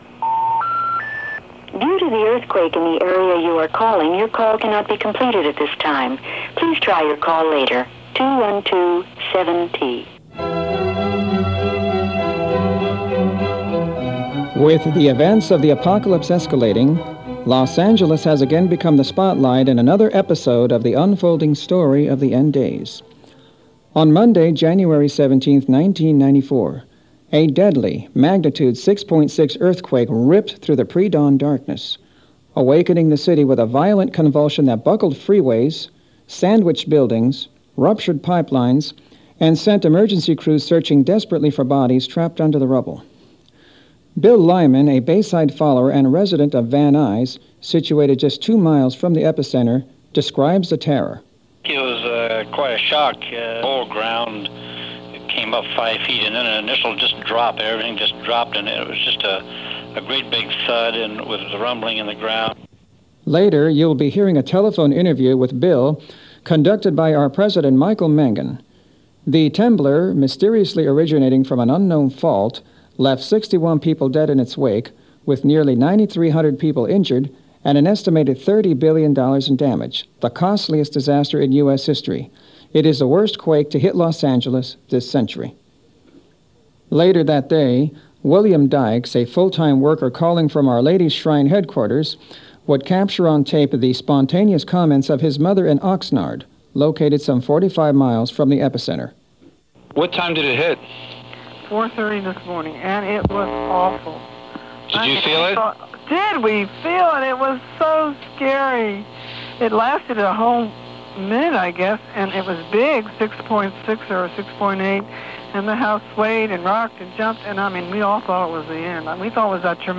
Click to listen to the meeting, or right-click to download the .mp3 audio file to your device.